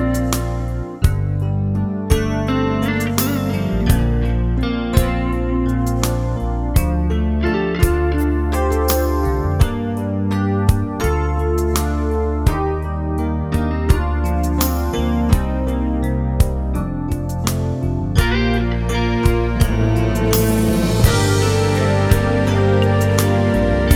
no Backing Vocals Soundtracks 4:50 Buy £1.50